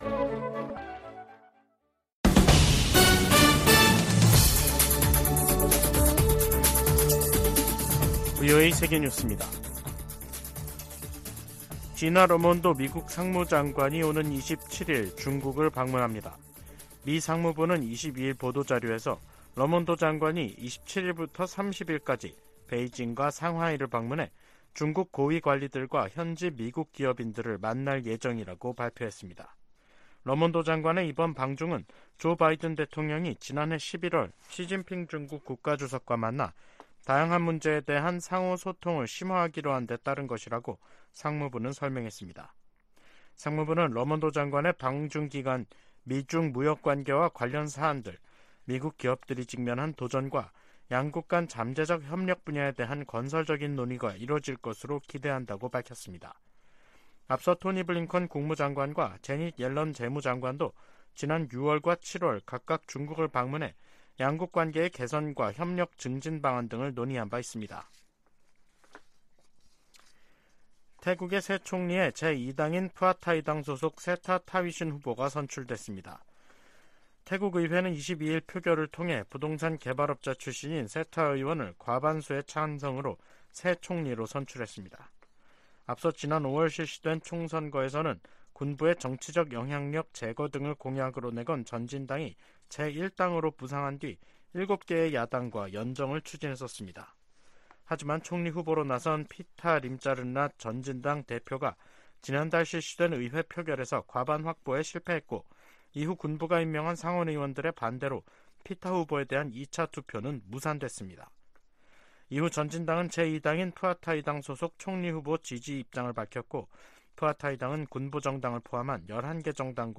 VOA 한국어 간판 뉴스 프로그램 '뉴스 투데이', 2023년 8월 22일 3부 방송입니다. 북한이 실패 3개월만에 군사정찰위성을 다시 발사하겠다고 예고했습니다. 백악관 국가안보회의(NSC) 인도태평양 조정관은 미한일 3국 협력이 위중해진 역내 안보를 지키기 위한 노력의 일환이라고 말했습니다. 6차례에 걸쳐 보내드리는 기획특집 [미한일 정상회의 결산] 첫 시간에 한층 격상된 3국 안보 협력 부분을 살펴봅니다.